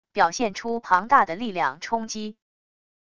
表现出庞大的力量冲击wav音频